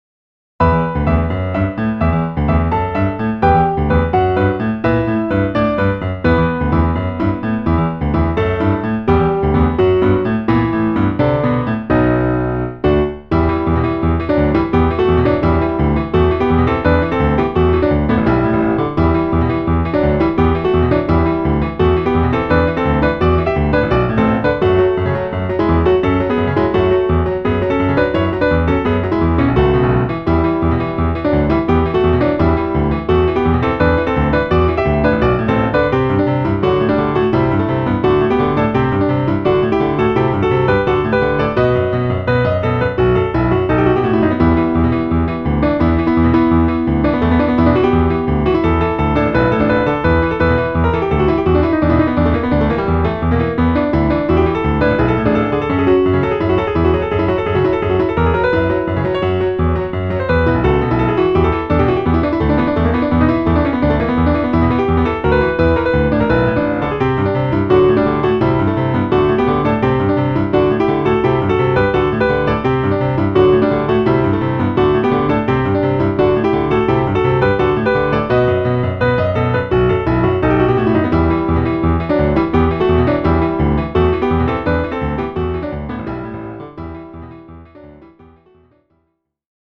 PIANO部屋 新着10曲分・一覧表示は こちら